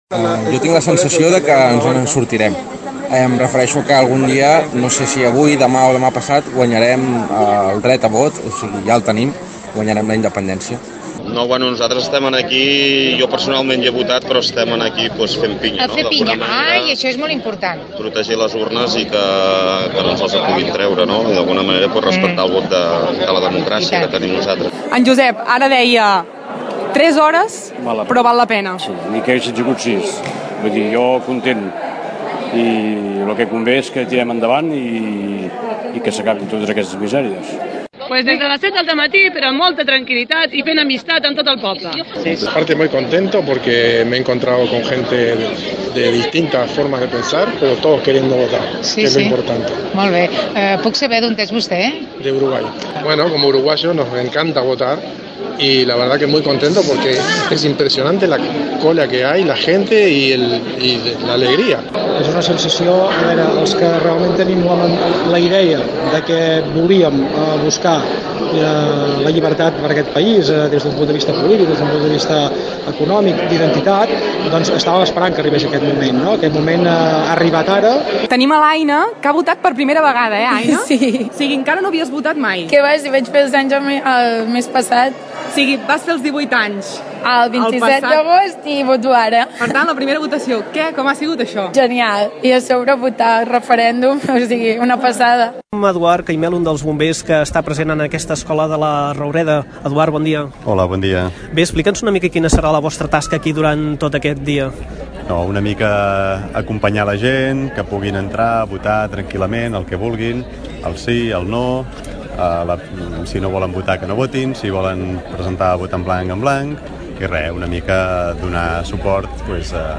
L’equip de Ràdio Tordera parlava aquell dia amb molts d’ells i així ens valoraven la jornada.